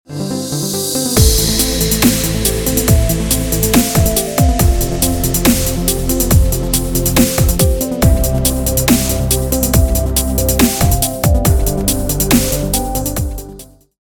رینگتون پرانرژی و بی کلام